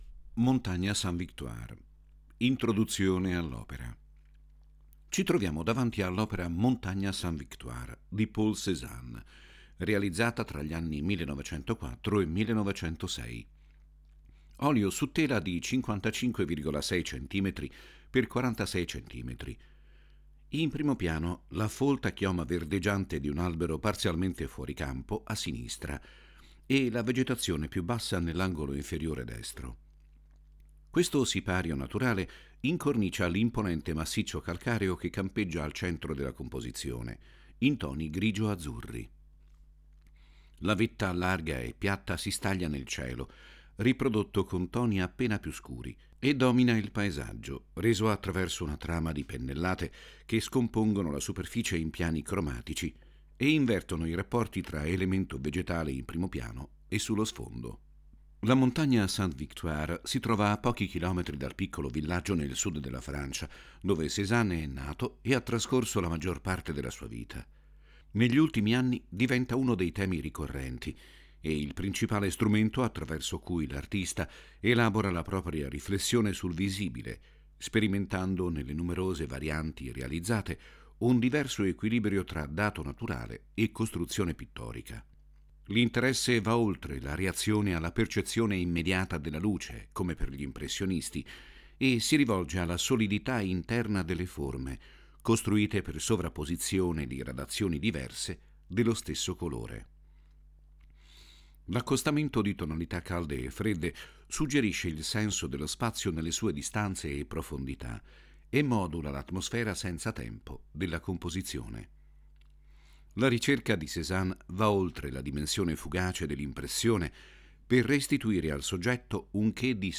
• 7 AUDIODESCRIZIONI DELLE OPERE che accompagnano il visitatore nell’esplorazione delle opere più significative della mostra, fruibili tramite QR code
Audiodescrizioni